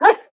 bark1.ogg